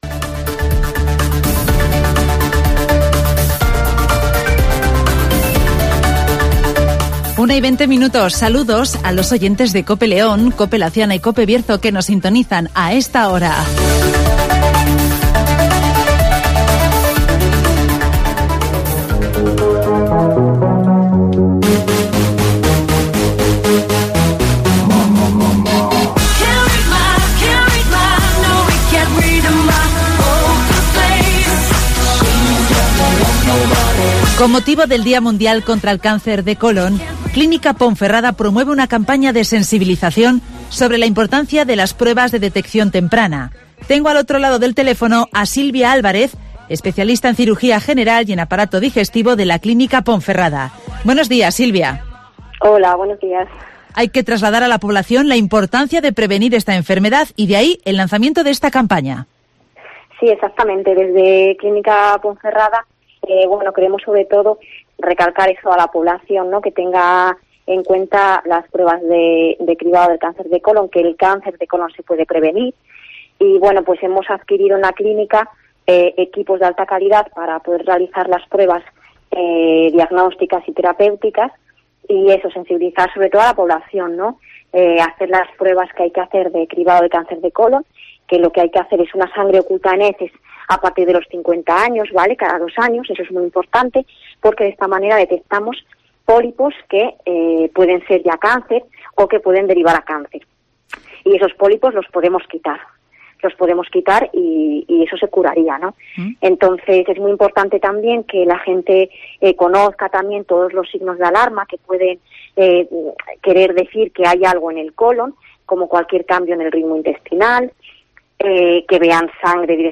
Clínica Ponferrada promueve una campaña sobre la importancia de la detección precoz del cáncer de cólon (Entrevista